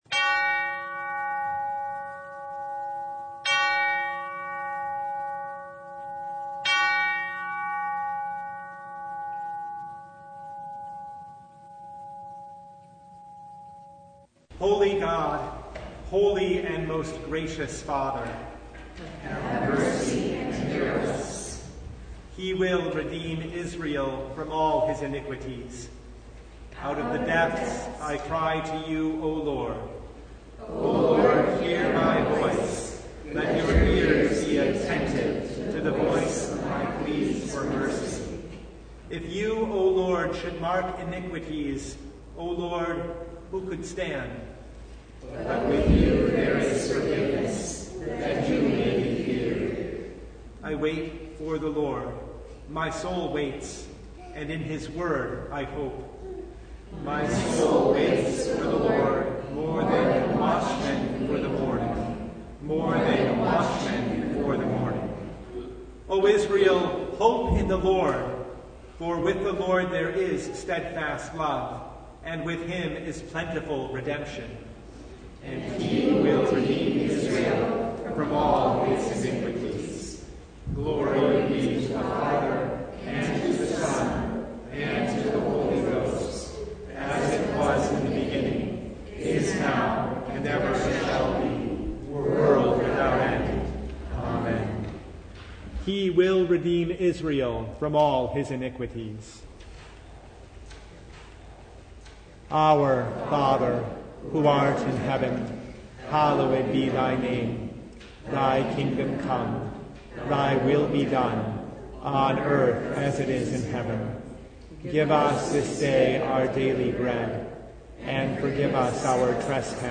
Service Type: Advent Noon
Full Service